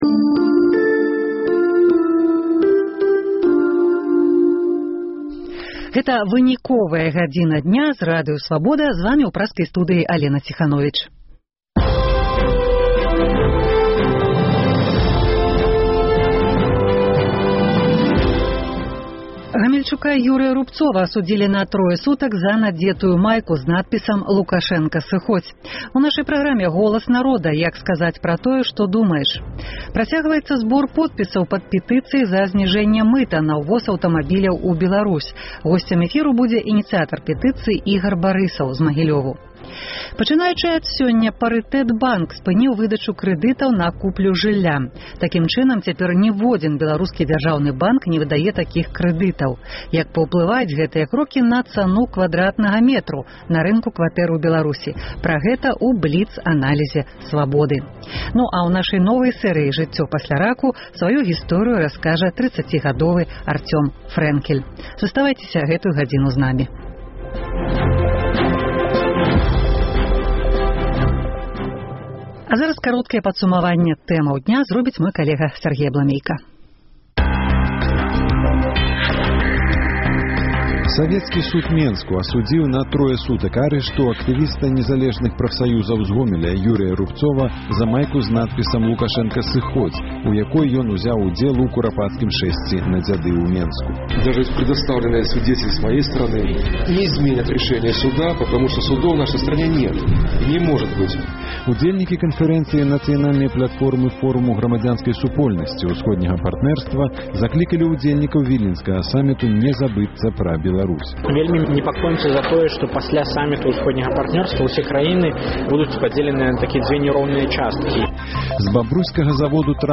Голас народа: а як сказаць пра тое, што думаеш? Больш чым 400 прадпрымальнікаў зьехаліся на форум у Менск, каб абмеркаваць захады супраць, як яны кажуць, вераломства ўраду. Працягваецца збор подпісаў пад пэтыцыяй за зьніжэньне мыта на ўвоз аўтамабіляў у Беларусь.